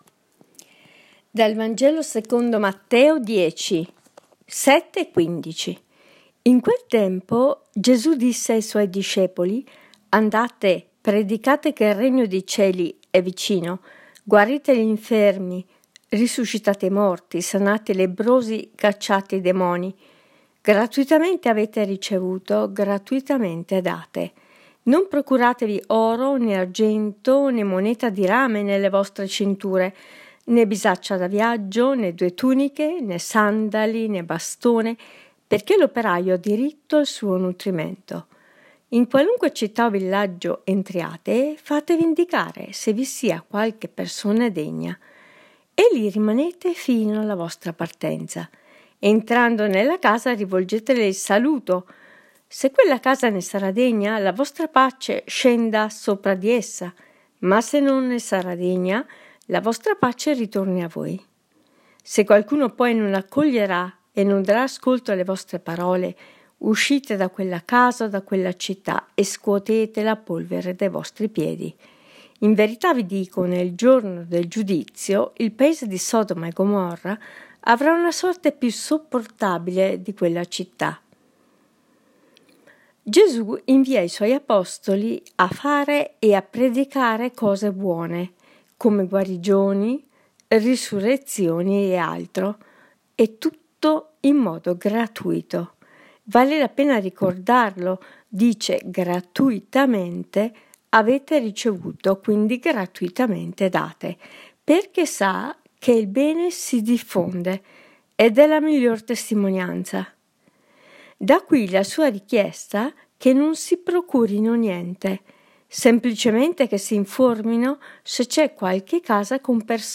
Commento al Vangelo